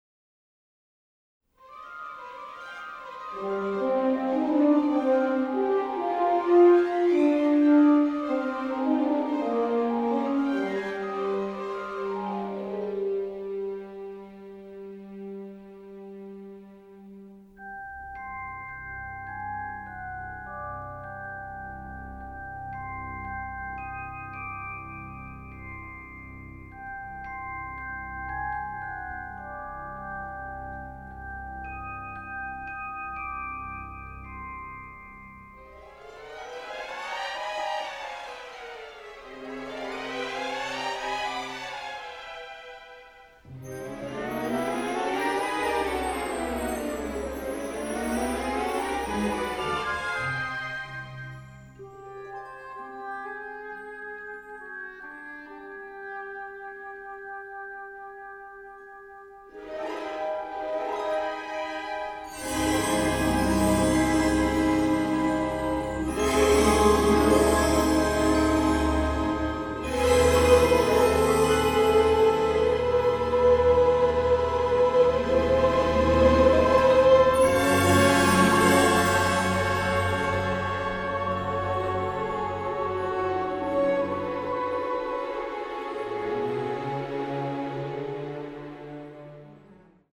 THE FILM SCORE